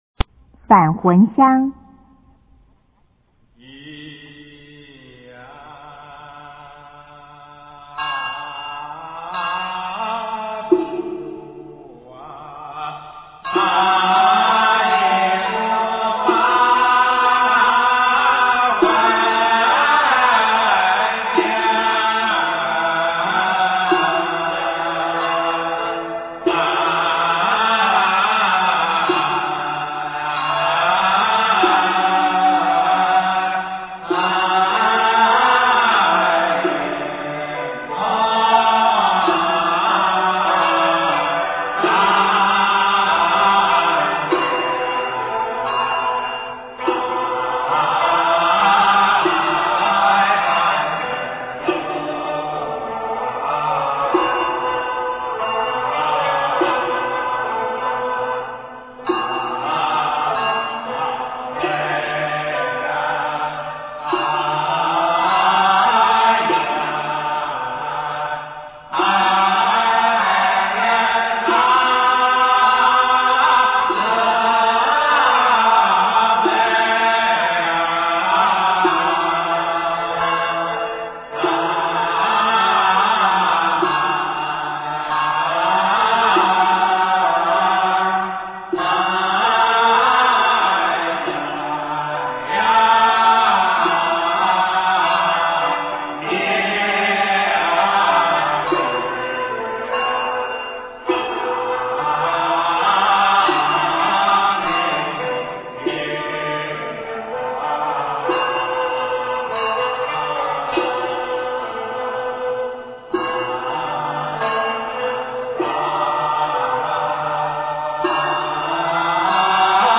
中国道教音乐 全真正韵 返魂香